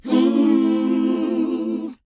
WHOA G BD.wav